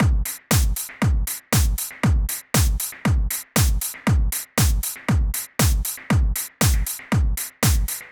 28 Drumloop PT1.wav